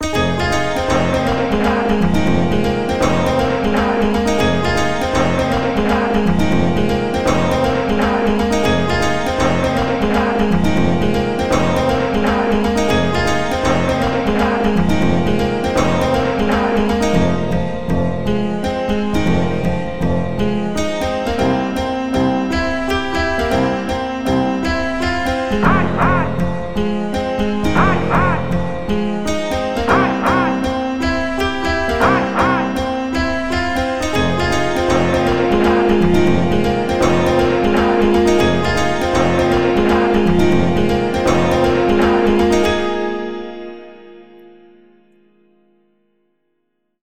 The Team 17 Anthem Remix!